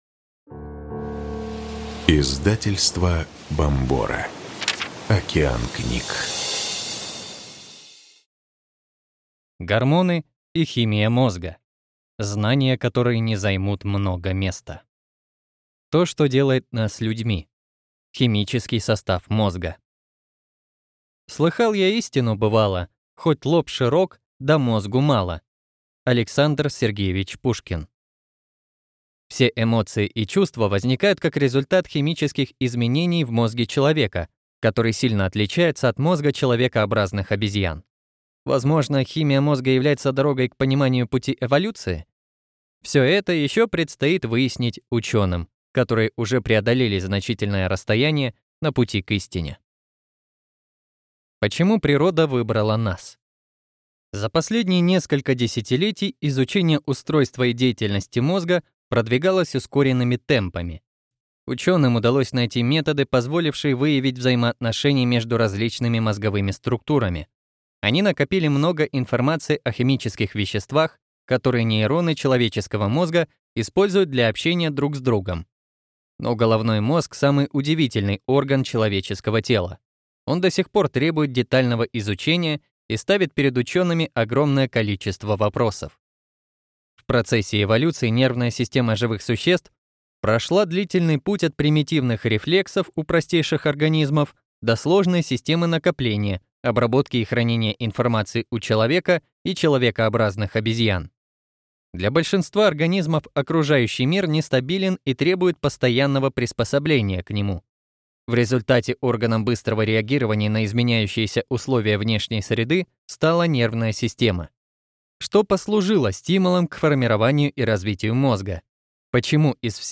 Аудиокнига Гормоны и химия мозга. Знания, которые не займут много места | Библиотека аудиокниг